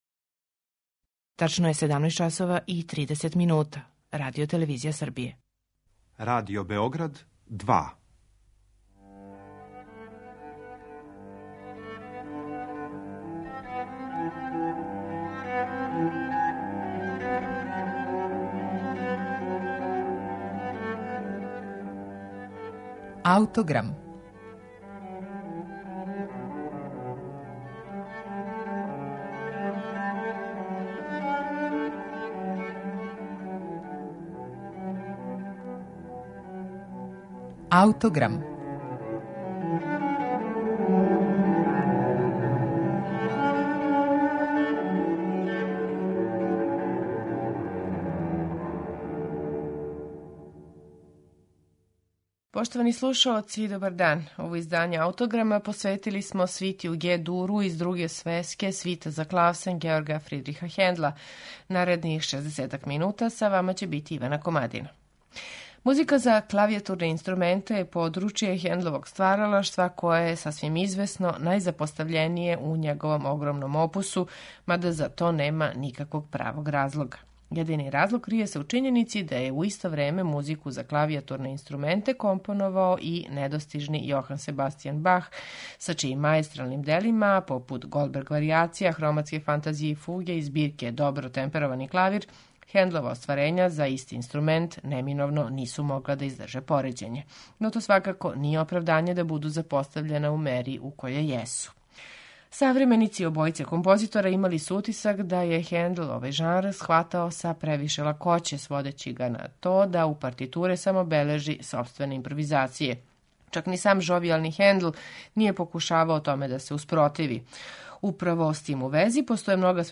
Слушаћете је у интерпретацији пијанисте Свјатослава Рихтера.